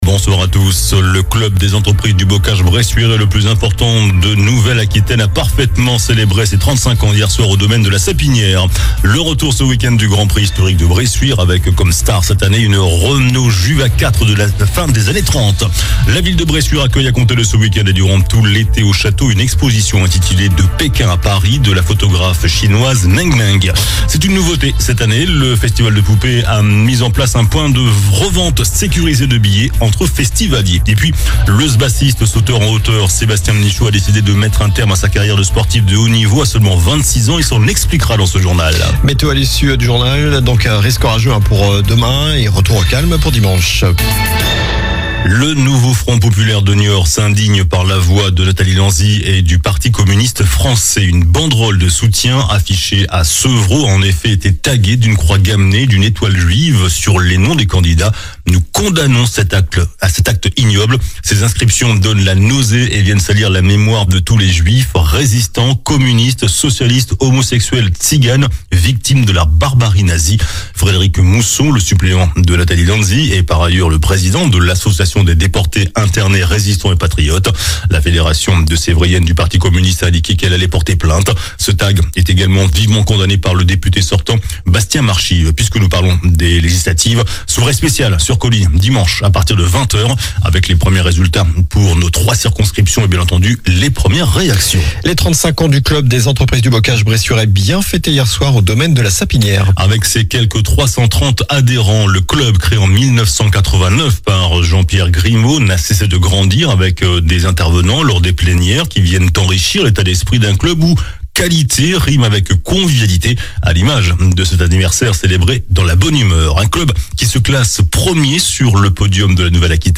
JOURNAL DU VENDREDI 28 JUIN ( SOIR )